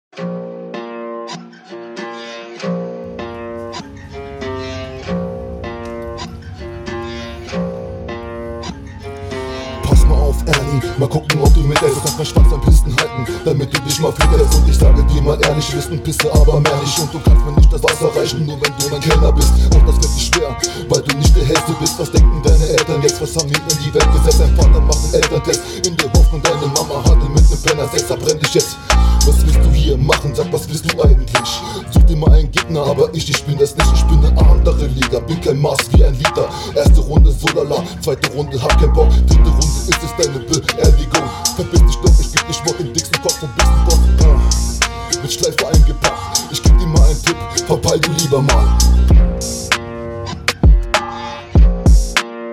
leider deine schwächste runde, man versteht dich kaum, war echt anstrengend, stimme zu leise, mische …
Abmische auch hier wieder anders als die anderen beiden.